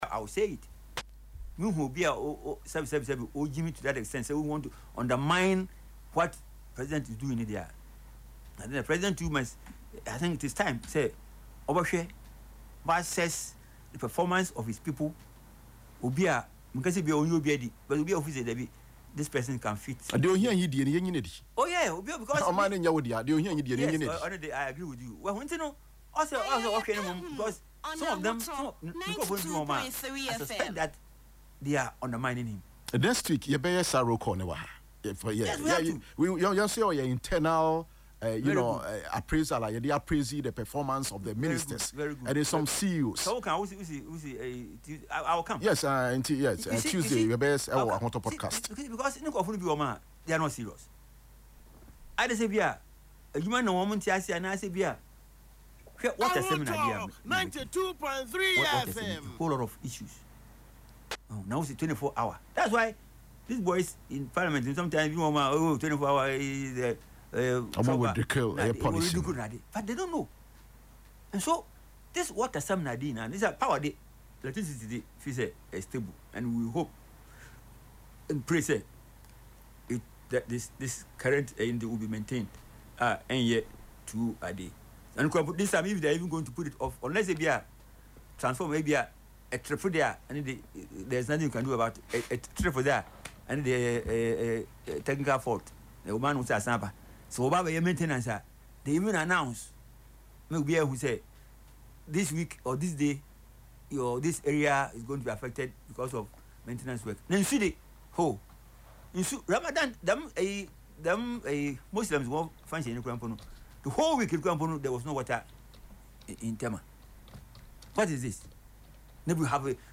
Speaking on Ahotor FM’s Yepe Ahunu show on Saturday, March 28, he expressed concern that some appointees may be undermining the President’s efforts through poor performance in their respective sectors.